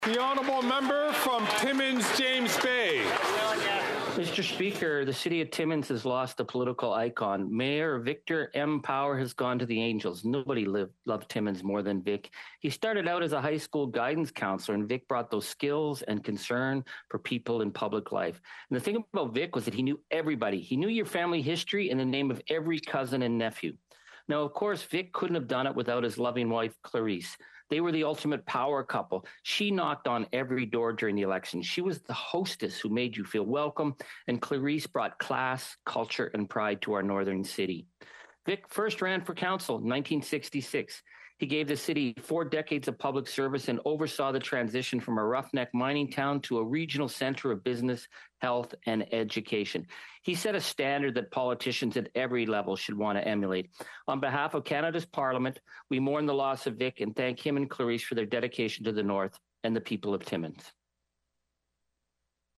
Charlie Angus delivers his tribute to Vic Power during Member Statements in the House of Commons.
Audio of Angus’s full statement is below.